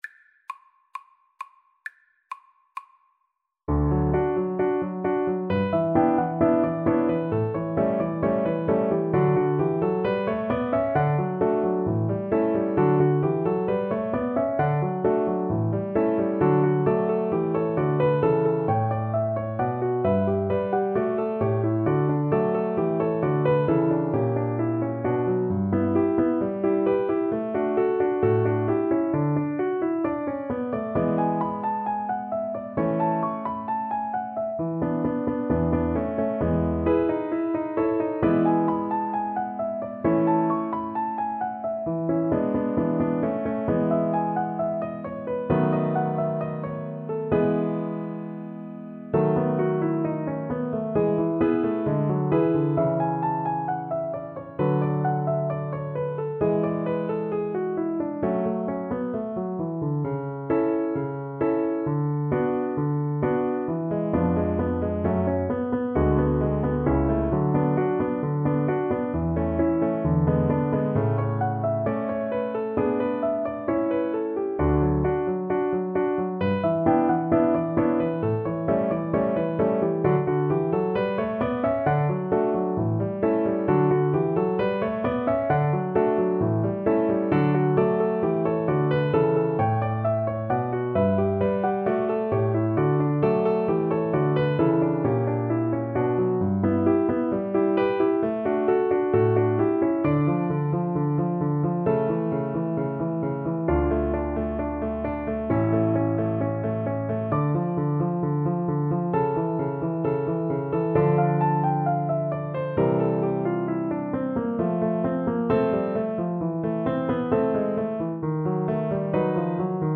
Play (or use space bar on your keyboard) Pause Music Playalong - Piano Accompaniment Playalong Band Accompaniment not yet available transpose reset tempo print settings full screen
D major (Sounding Pitch) (View more D major Music for Violin )
=132 Allegro assai (View more music marked Allegro)
Classical (View more Classical Violin Music)
mi_tradi_quell_alma_VLN_kar1.mp3